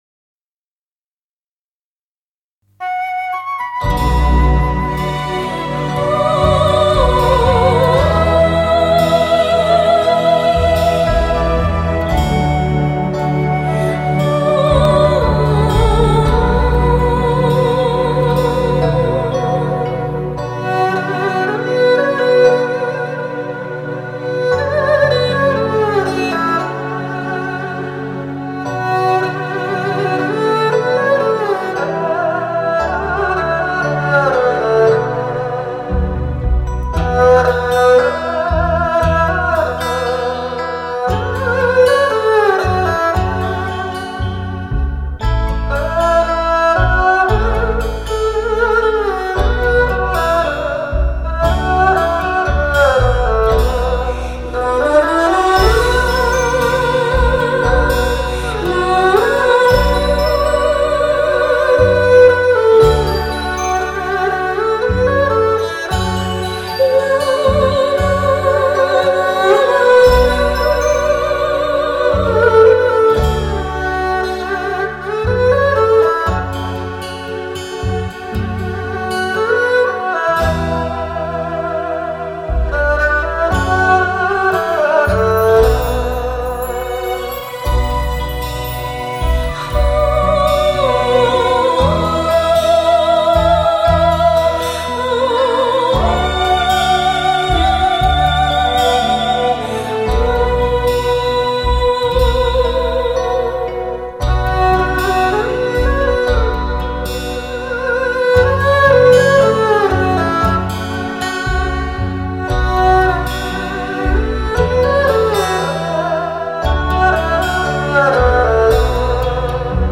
本辑精选用二胡演绎经典流行名曲，古今壁合，使经典名曲内涵更为深刻而脱俗。
二胡是中华民族最传统拉奏乐器之一，善于表现衷怨的音乐，充满深情、耐人寻味，旋律深远而恬静。